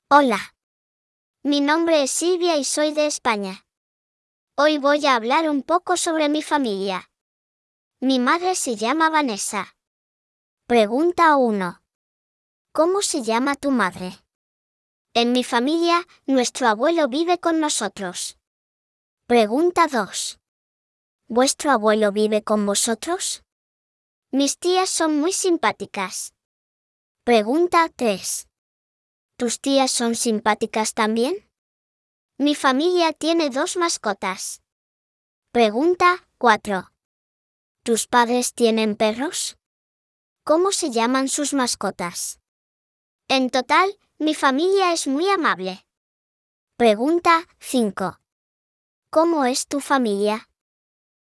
Sylvia is going to share some details about her family with you.